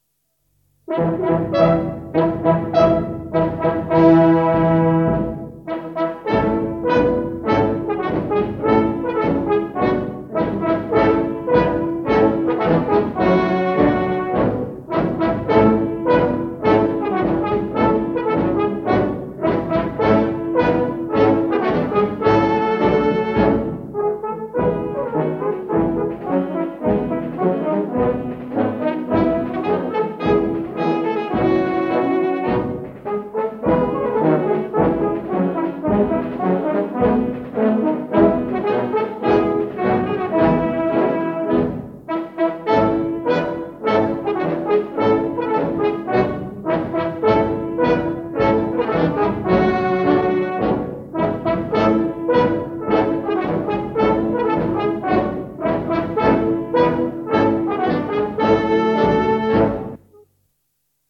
Type : chanson narrative ou de divertissement Aire culturelle d'origine : Pays d'Ourthe-Amblève Interprète(s) : Anonyme (femme) Date d'enregistrement : 15 avril 1961